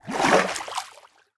sounds / mob / dolphin / jump2.ogg
jump2.ogg